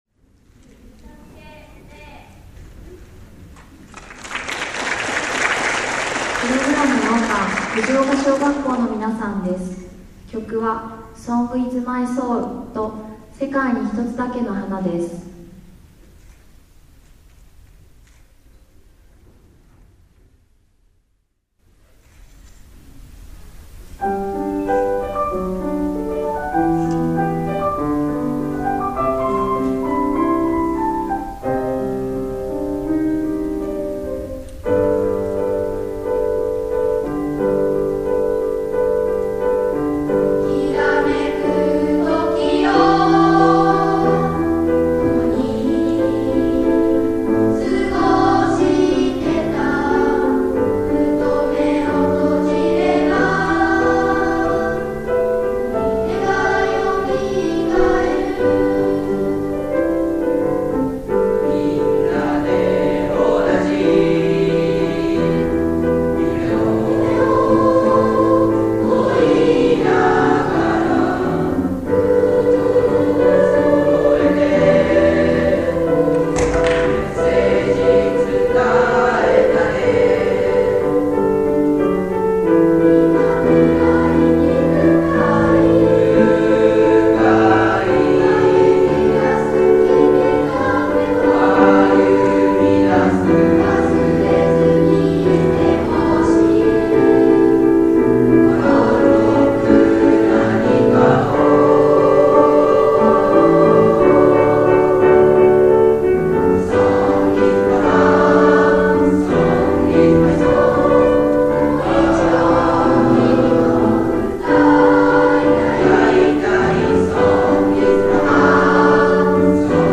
音楽 わたらせ風の子音楽祭参加
下の曲名をクリックすると、６年生の演奏を聞くことができます。
合唱の様子　　　　　　　　　　　　　　合奏の様子